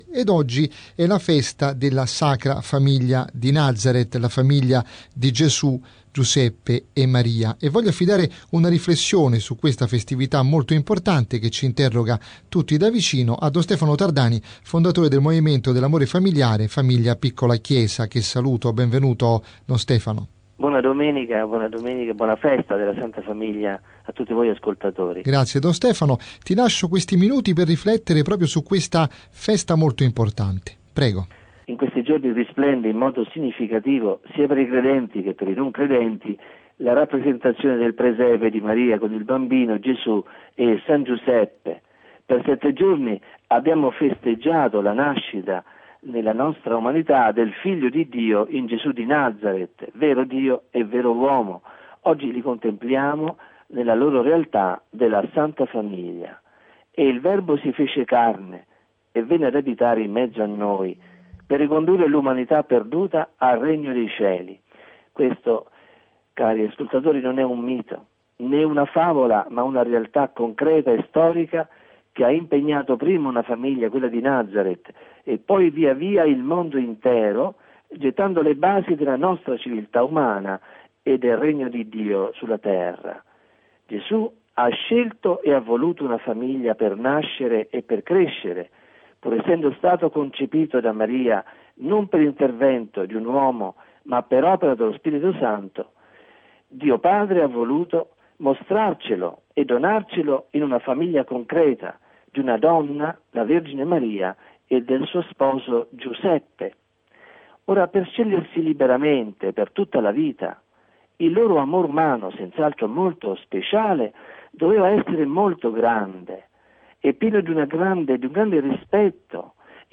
Meditazione sul significato di questa festa per la famiglia di oggi.